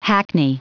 Prononciation du mot hackney en anglais (fichier audio)
Prononciation du mot : hackney